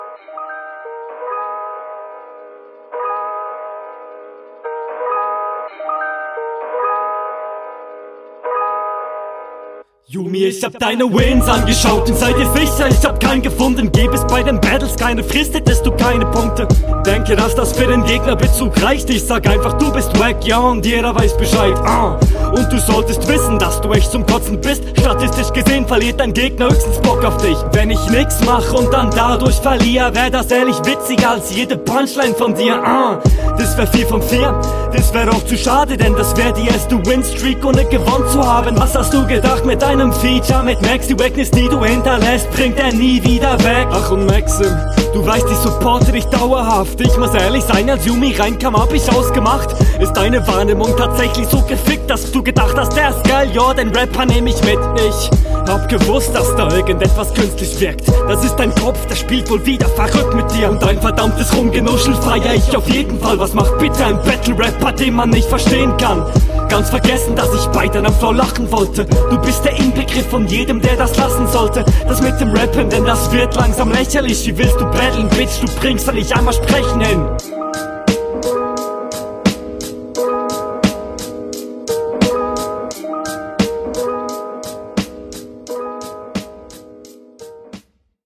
Der Beat passt viel besser zu dir (logisch, …